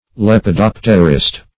Lepidopterist \Lep`i*dop"ter*ist\, n. (Zool.)